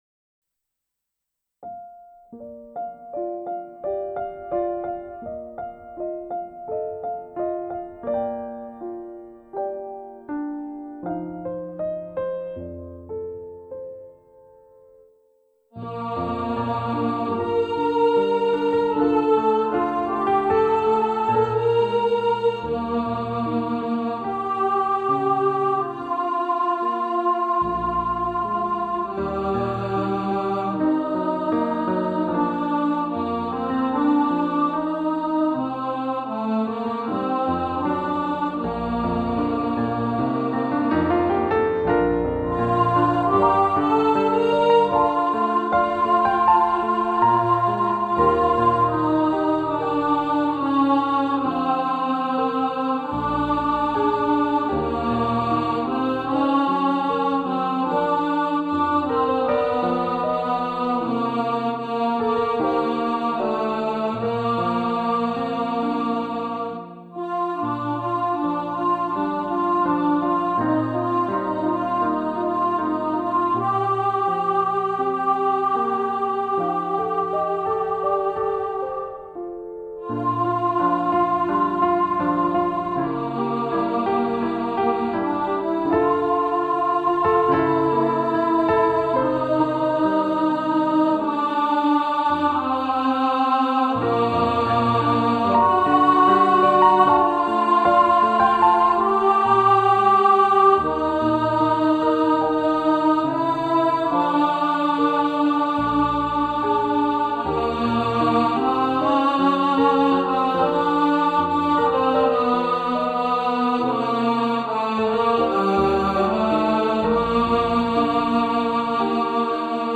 Over The Rainbow Alto | Ipswich Hospital Community Choir